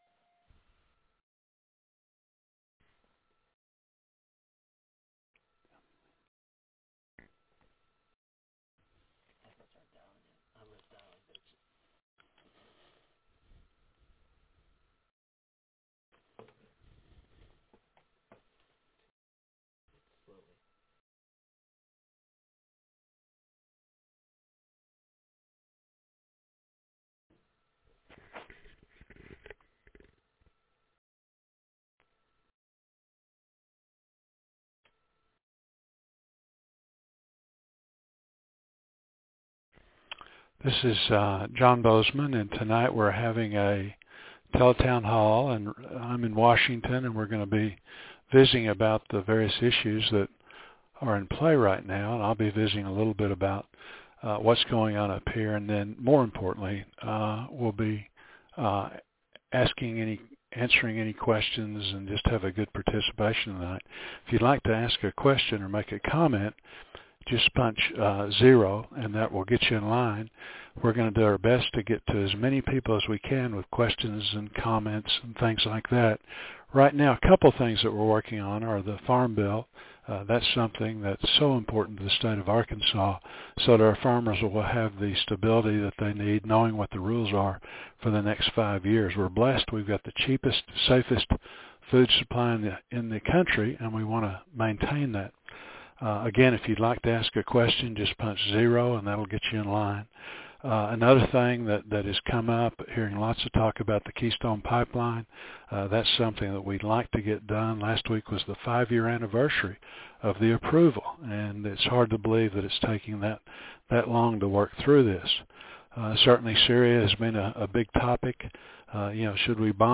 Tele-Town Hall